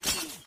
zipline_out.wav